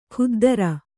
♪ khuddara